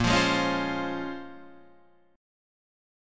A#m9 chord